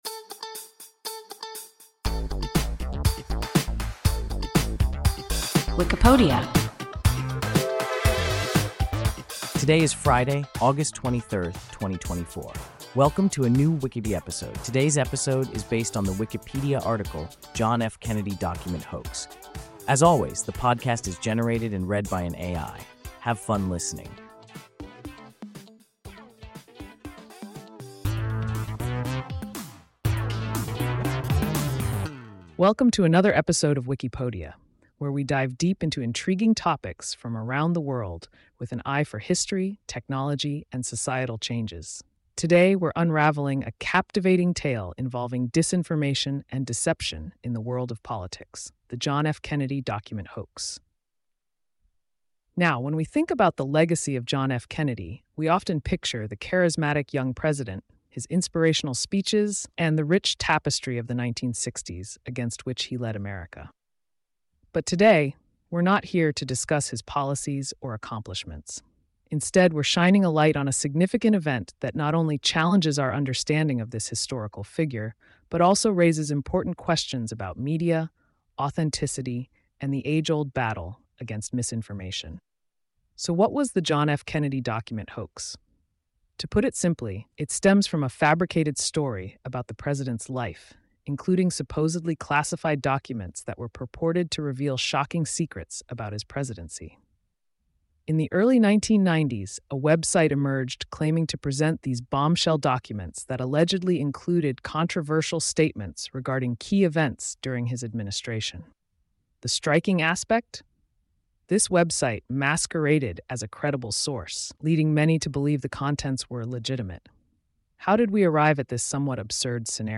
John F. Kennedy document hoax – WIKIPODIA – ein KI Podcast